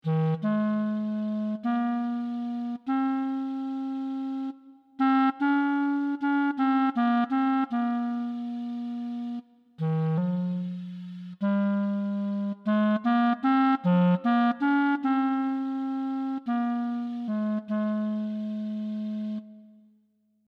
As a first approach, we have assumed that a MIDI-controlled digital clarinet synthesiser based on physical models is a sufficiently good instrument model.